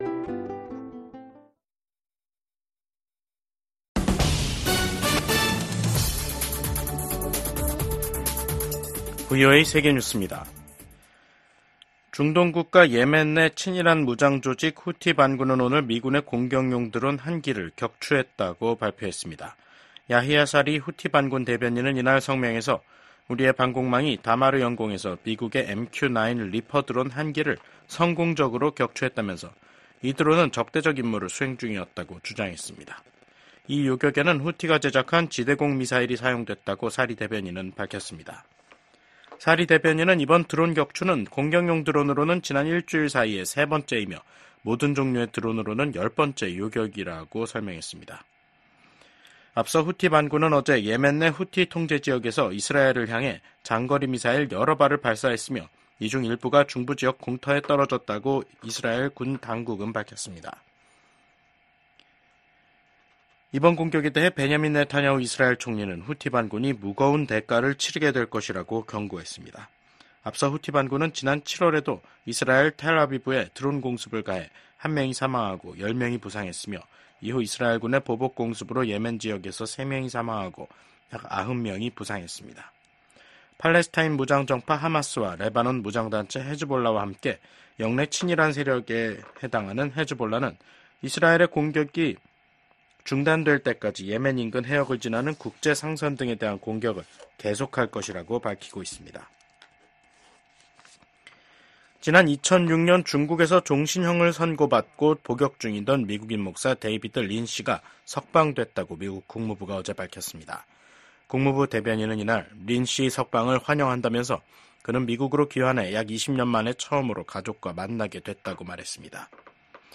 VOA 한국어 간판 뉴스 프로그램 '뉴스 투데이', 2024년 9월 16일 2부 방송입니다. 미국 백악관은 북한이 우라늄 농축시설을 공개한 것과 관련해 북한의 핵 야망을 계속 감시하고 있다고 밝혔습니다. 북한은 다음달 초 최고인민회의를 열고 헌법 개정을 논의한다고 밝혔습니다.